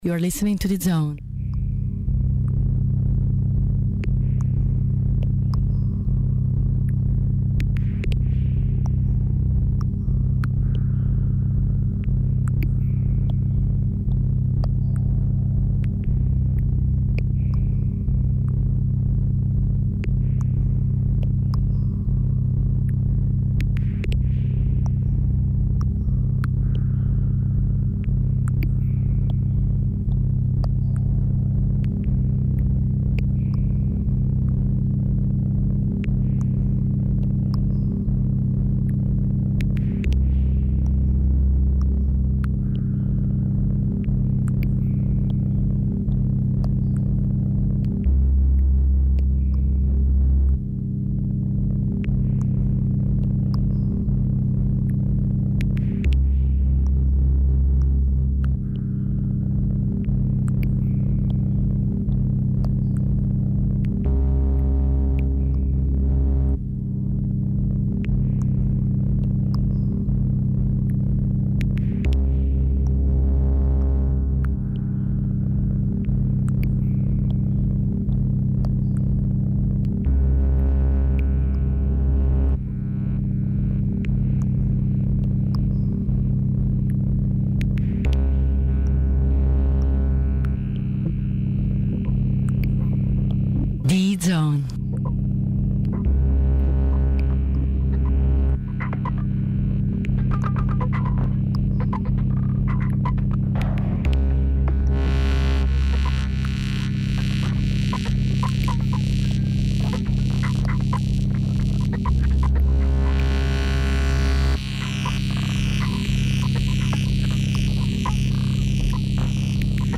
Onair with a great LIVE SET
a great journey into Electronic waves